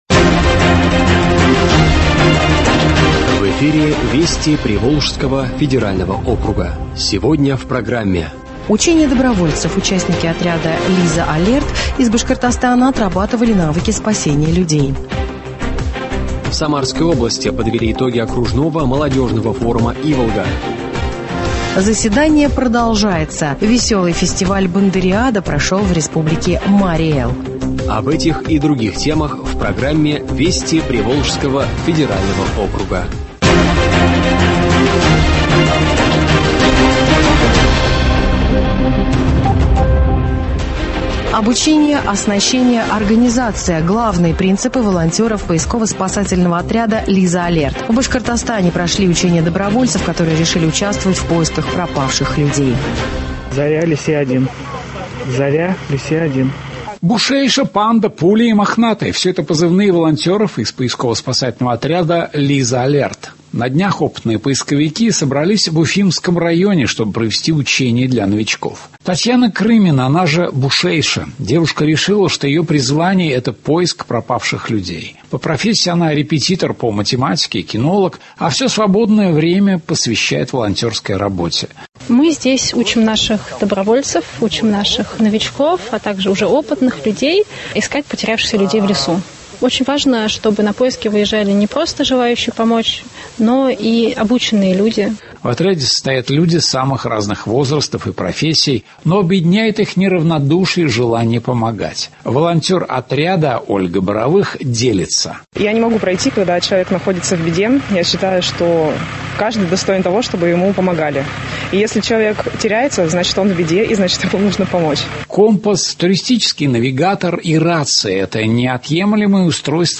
Радиообзор событий недели в регионах ПФО.